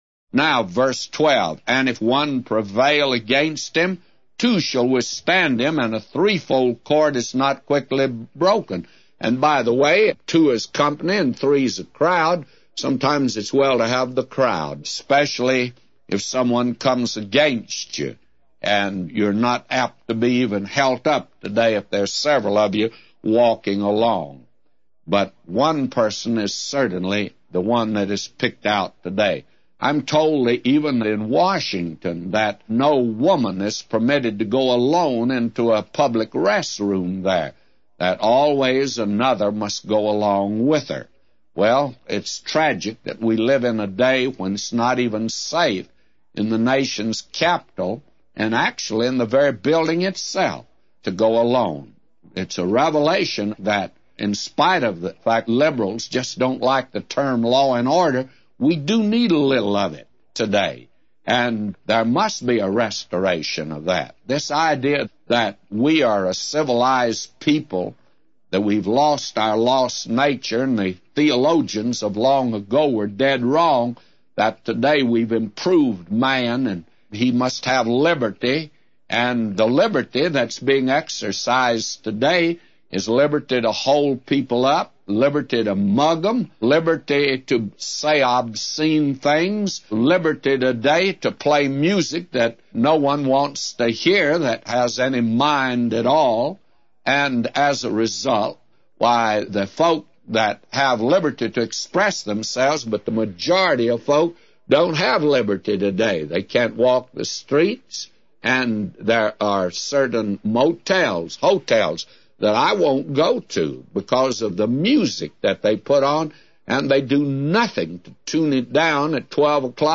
A Commentary By J Vernon MCgee For Ecclesiastes 4:12-999